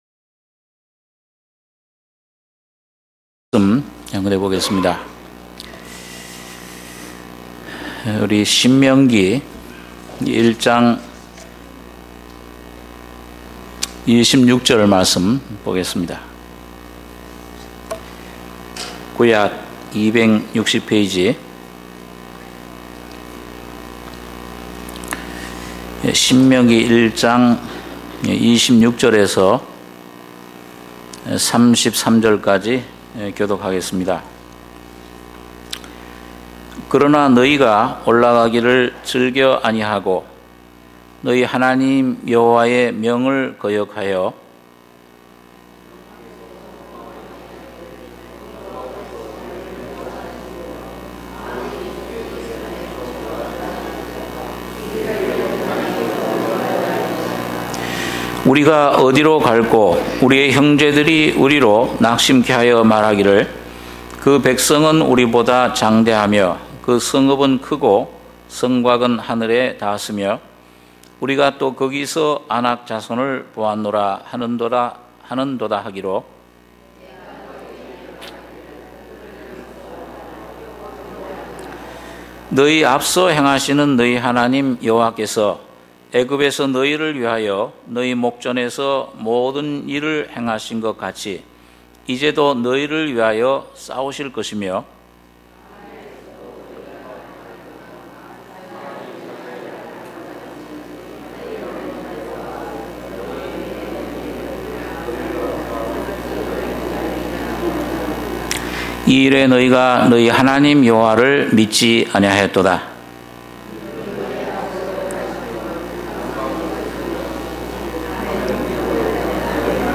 주일예배 - 신명기 1장 26절~33절 2부